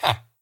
Sound / Minecraft / mob / villager / yes3.ogg